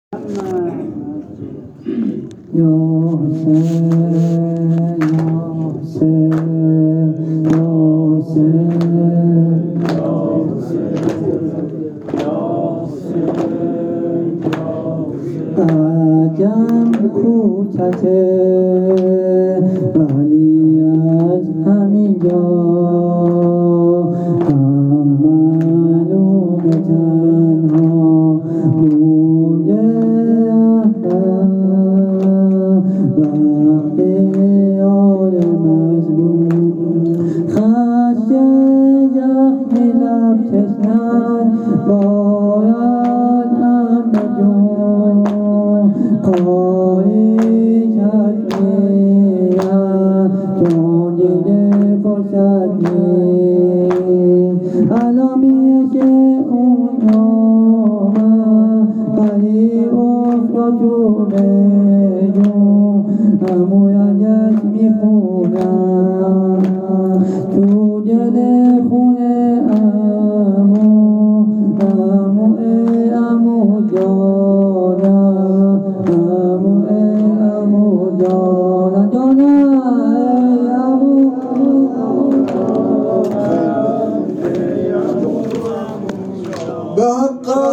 هیت هفتگی عشاق العباس تهران